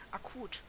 Ääntäminen
IPA: /akˈuːt/ IPA: [ʔakʰˈuːtʰ]